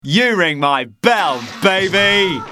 • Soundtrack Ringtones